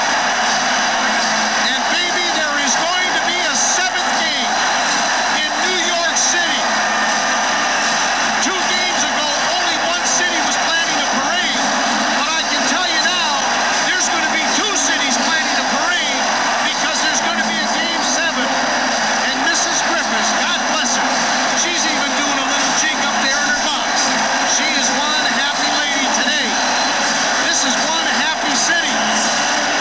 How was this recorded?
Download Part 4 of the dying moments of Game Six in Vancouver, the 'Best game ever played at the Pacific Coliseum.'